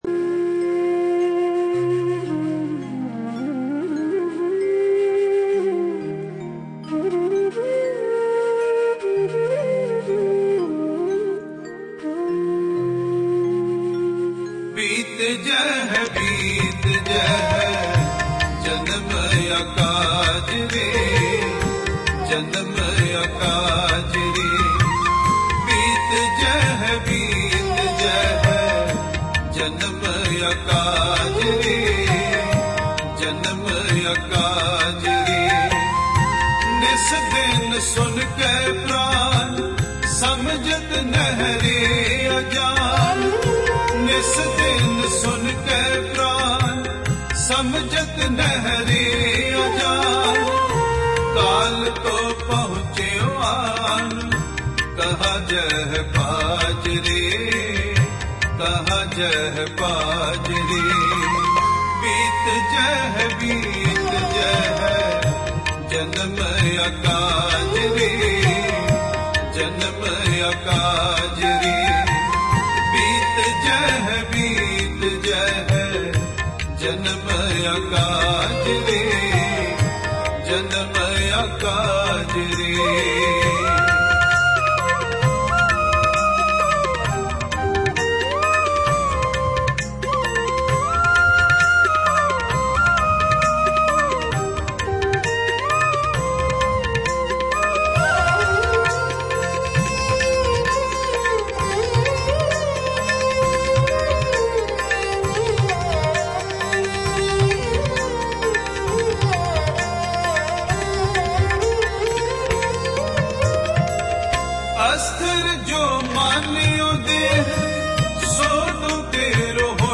Gurbani Shabad Kirtan